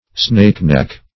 snakeneck - definition of snakeneck - synonyms, pronunciation, spelling from Free Dictionary Search Result for " snakeneck" : The Collaborative International Dictionary of English v.0.48: Snakeneck \Snake"neck`\, n. (Zool.) The snakebird, 1.